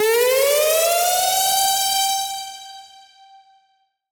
Index of /musicradar/future-rave-samples/Siren-Horn Type Hits/Ramp Up
FR_SirHornF[up]-G.wav